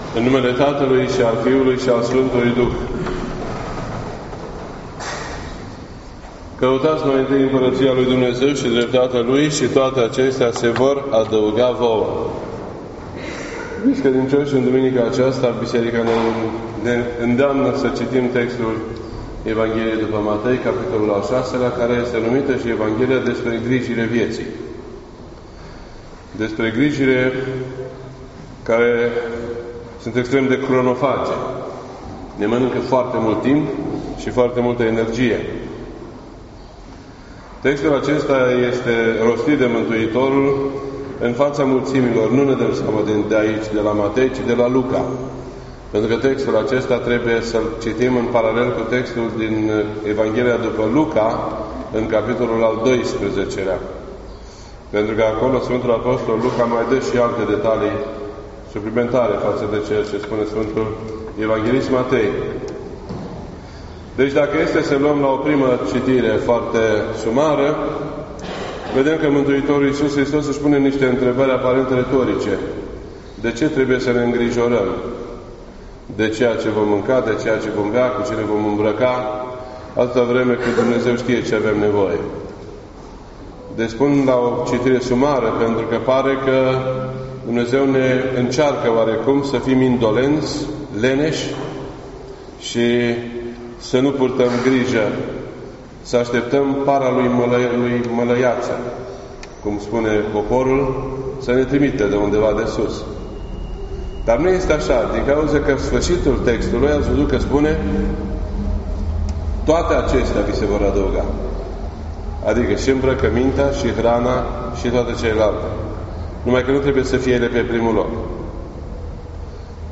This entry was posted on Sunday, June 17th, 2018 at 1:33 PM and is filed under Predici ortodoxe in format audio.